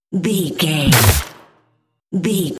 Dramatic hit hiss electricity debris
Sound Effects
heavy
intense
dark
aggressive
hits